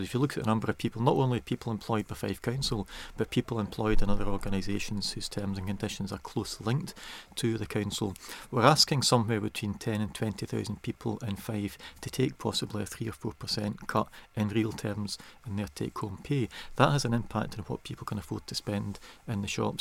Here's Fife SNP Leader Peter Grant: